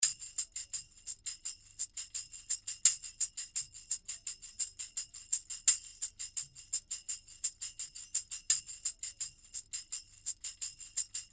85 BPM Tambourine (5 variations)
free tambourine loops, playing in 85 bpm
Tambourine loops in 5 variations playing in 85 bpm.